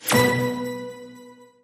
Win_Frame_Sound.MP3